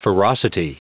Prononciation du mot ferocity en anglais (fichier audio)